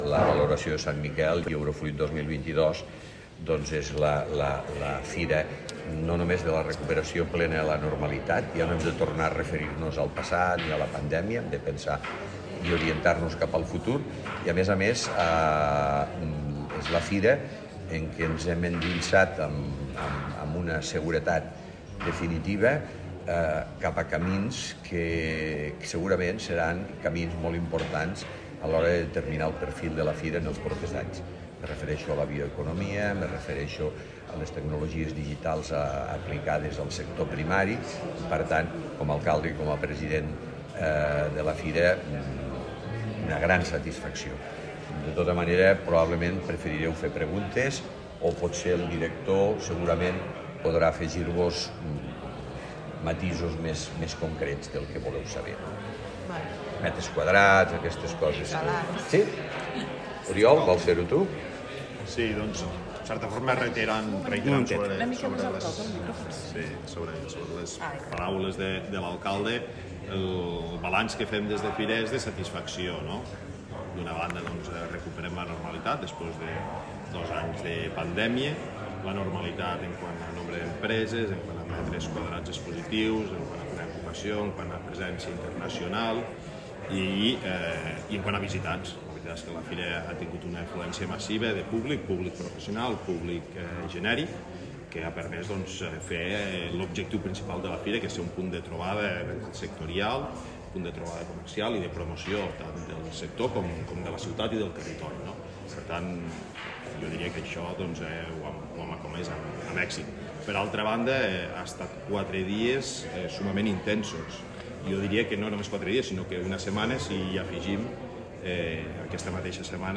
tall-de-veu-de-lalcalde-de-lleida-miquel-pueyo-de-valoracio-de-la-68a-edicio-de-la-fira-de-sant-miquel